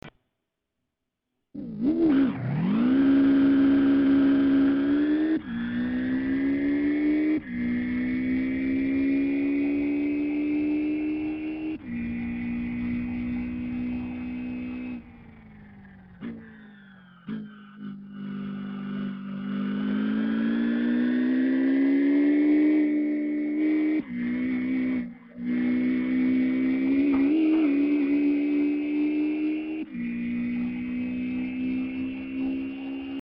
Recording somehow works now (only mono and I have to set it to "microphone" instead of the appropriate "stereo mix"). So, here's the weird sound "off the line" I was talking about (just shortly after the recording begins):